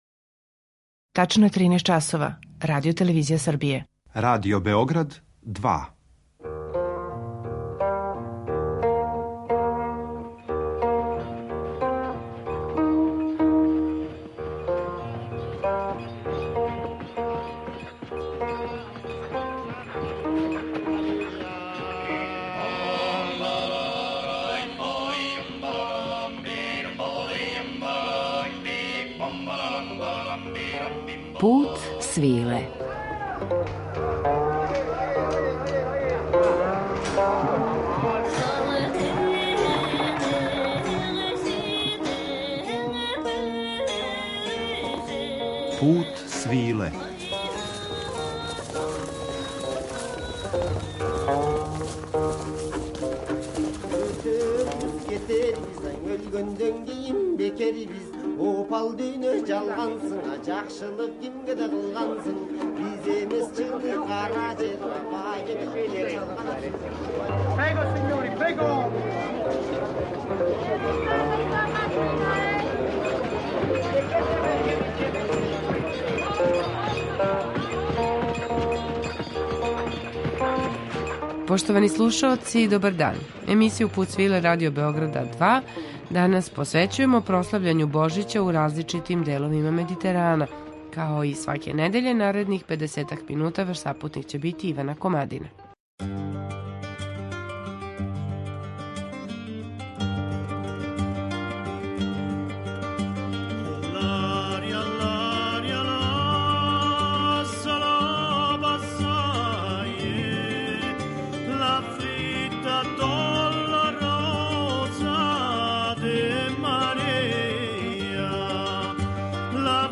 Обичајима који широм Медитерана прате прославу Божића, као и песмама у славу Богородице, посветили смо данашњи Пут свиле . У интерпретацији групе „Rassegna", слушаћете традиционалне божићне песме са Крита, Сицилије, Корзике, италијанског и шпанског југа, Тракије...